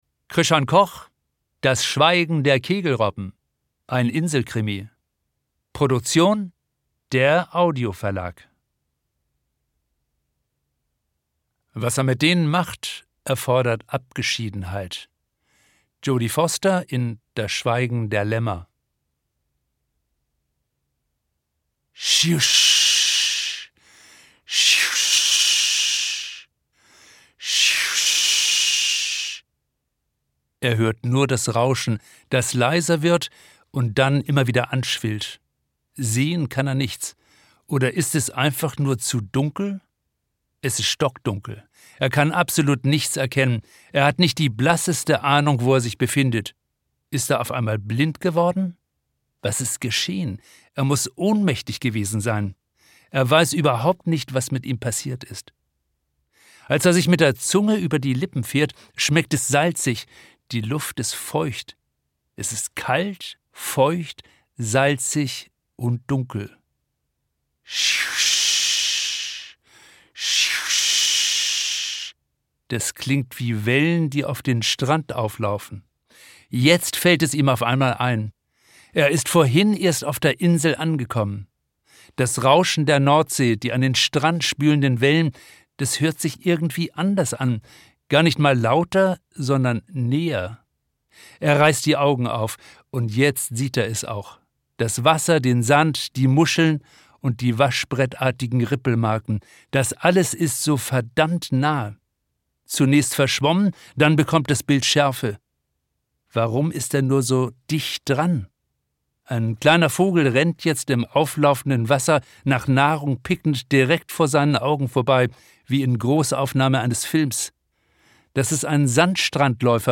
Ungekürzte Autorenlesung